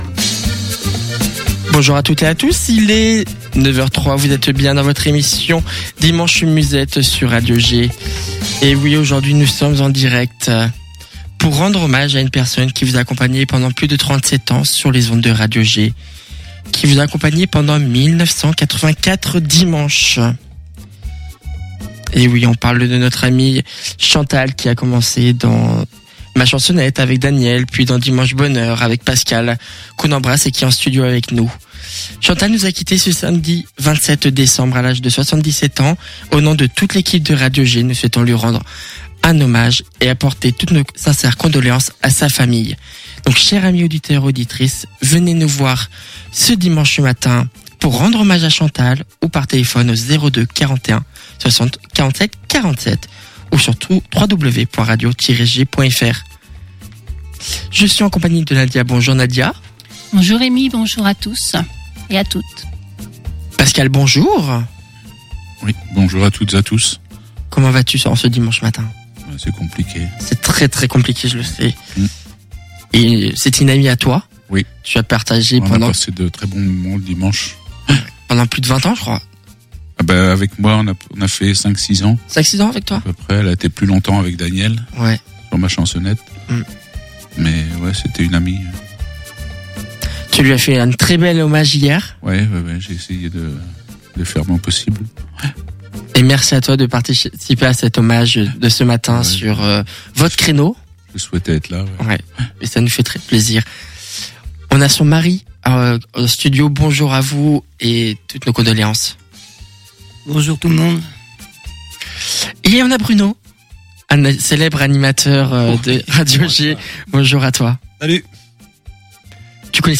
Débutez vos dimanches matin en musette !